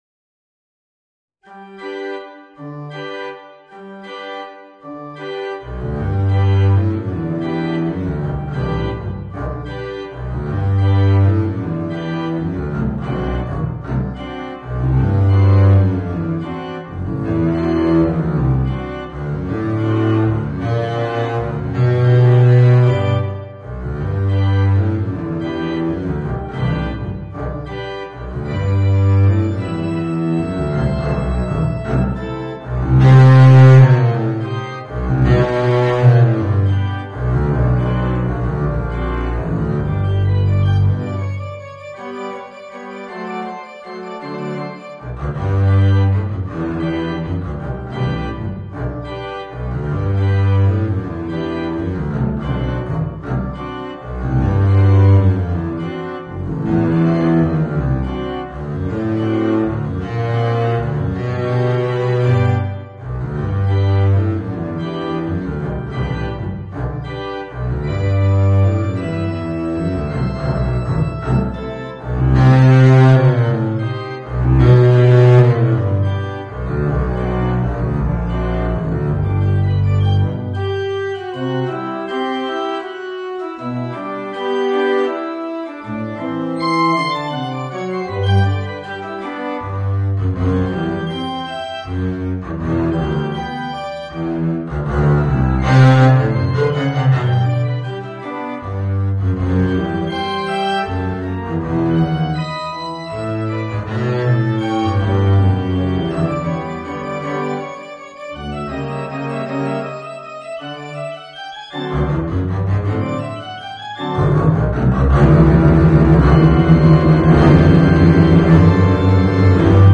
Voicing: Contrabass and Piano